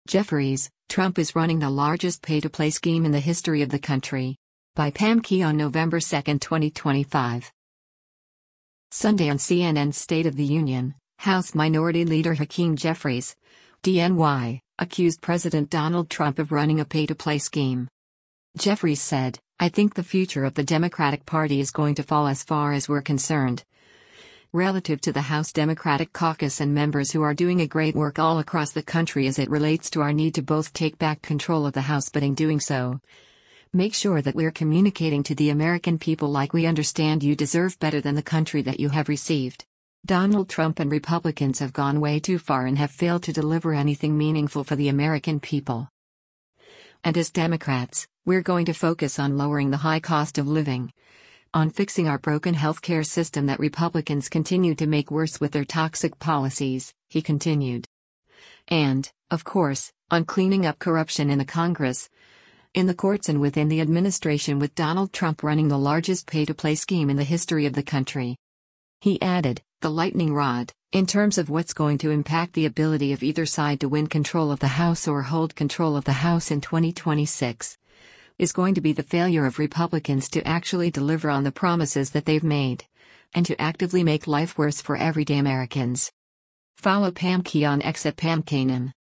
Sunday on CNN’s “State of the Union,” House Minority Leader Hakeem Jeffries (D-NY) accused President Donald Trump of running a “pay to play scheme.”